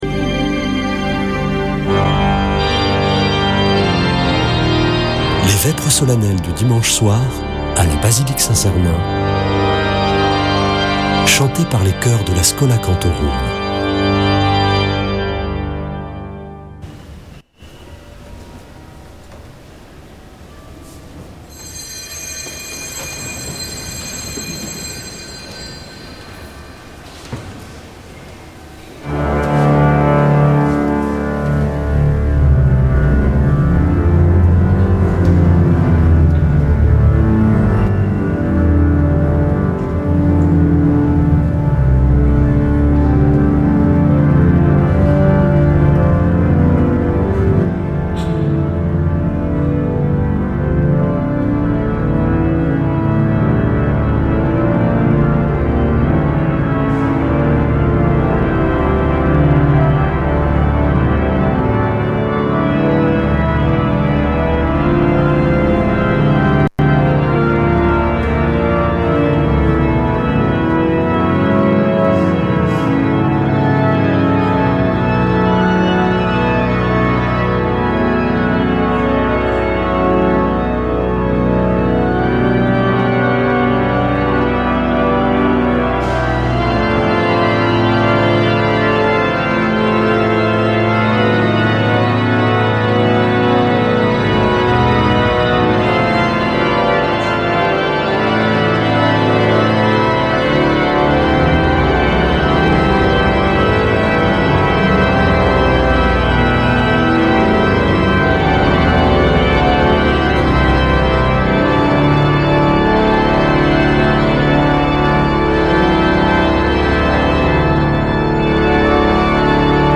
Une émission présentée par Schola Saint Sernin Chanteurs